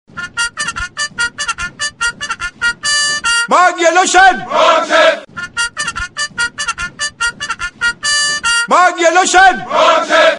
Категория: Разные звуки